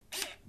Звуки зума
Короткий